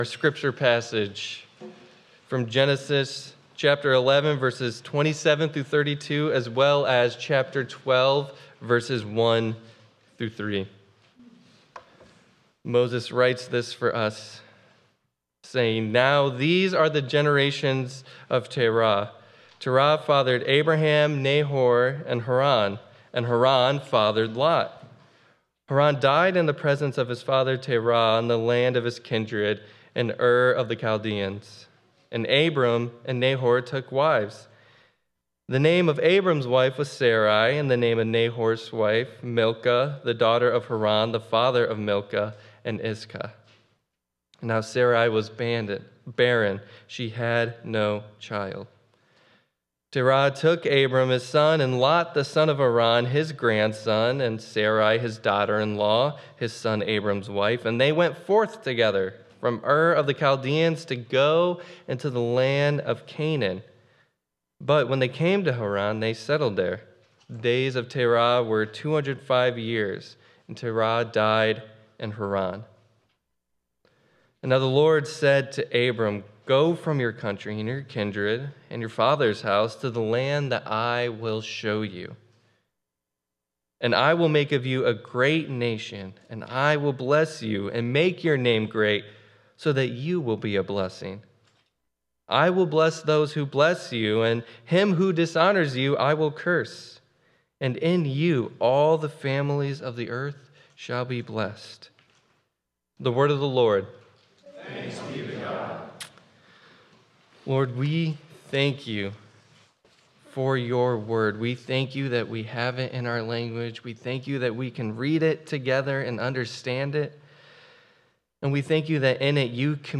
12.7.25 Sermon.m4a